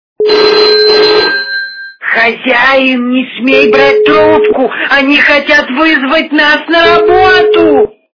При прослушивании Говорящий телефон - Хозяин, не бери трубку. Они могут вызвать нас на работу! качество понижено и присутствуют гудки.